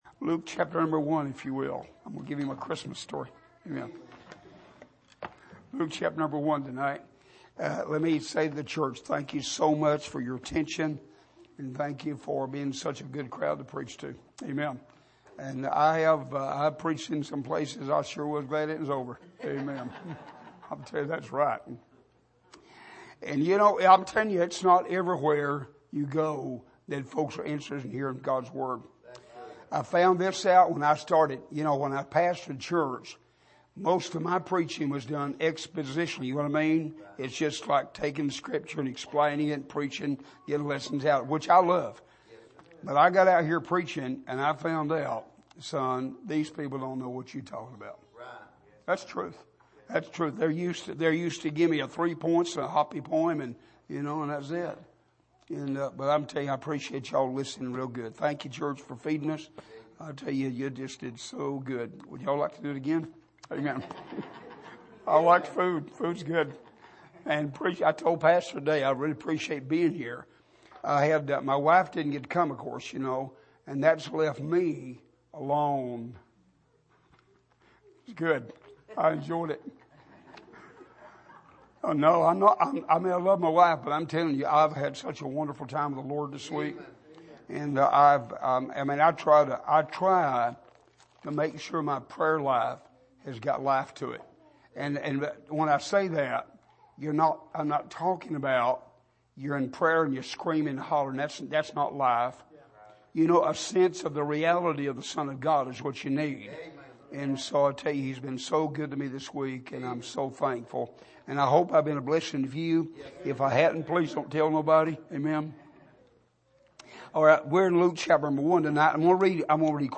Here is an archive of messages preached at the Island Ford Baptist Church.
Service: Sunday Morning